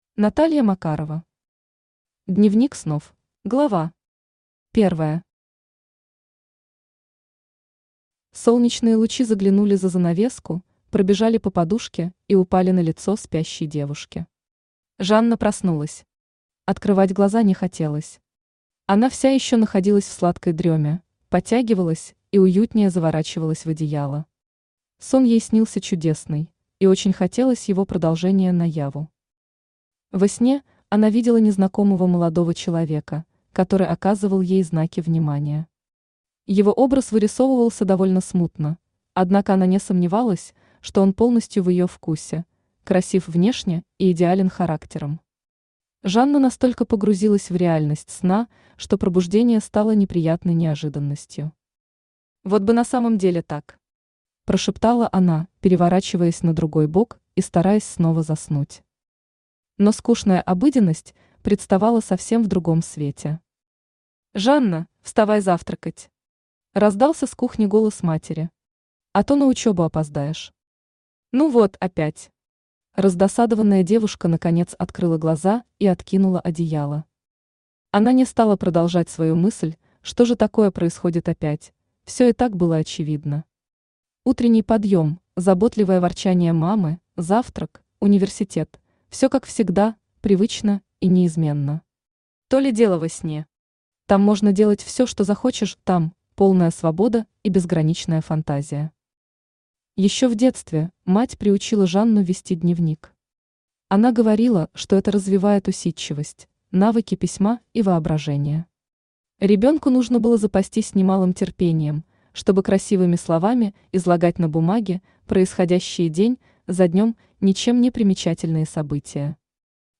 Aудиокнига Дневник снов Автор Наталья Макарова Читает аудиокнигу Авточтец ЛитРес.